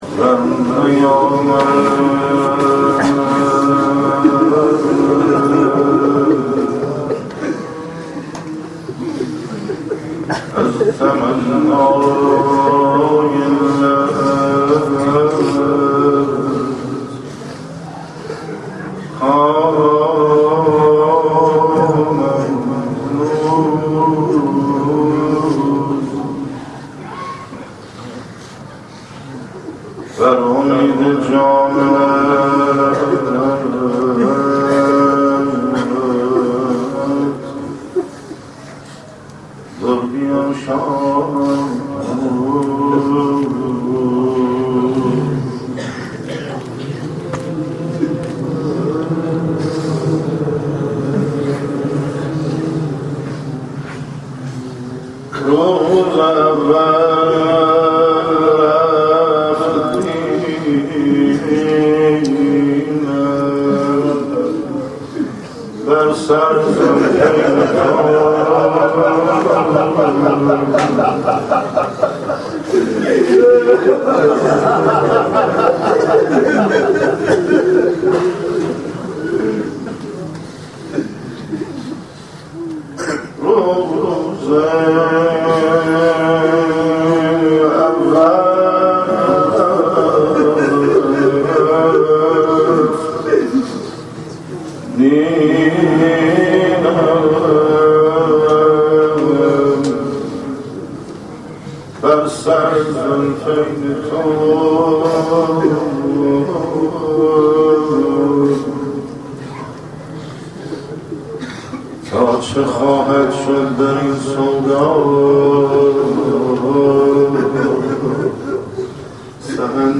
عقیق: مراسم سخنرانی و ذکر توسل طبق روال هر هفته در حسینیه جلوه برگزار شد.